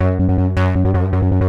hous-tec / 160bpm / bass
wwbass-3.mp3